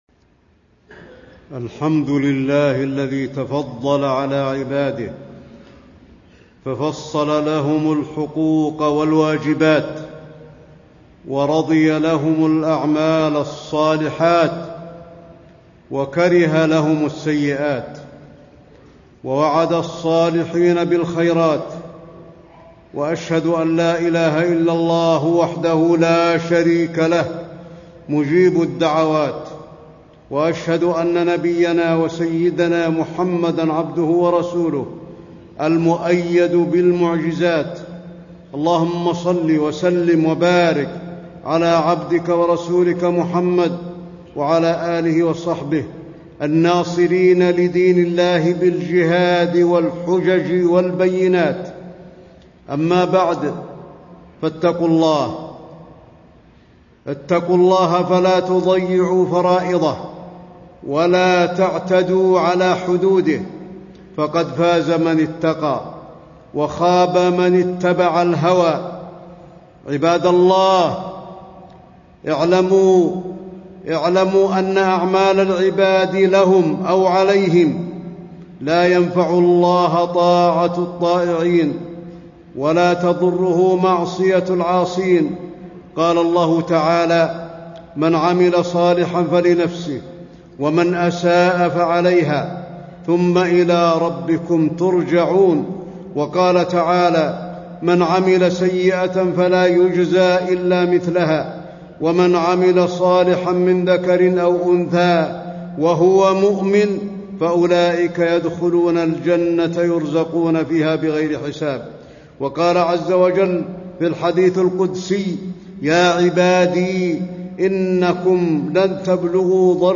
تاريخ النشر ١٠ ذو القعدة ١٤٣٥ هـ المكان: المسجد النبوي الشيخ: فضيلة الشيخ د. علي بن عبدالرحمن الحذيفي فضيلة الشيخ د. علي بن عبدالرحمن الحذيفي الحقوق الواجبة على العباد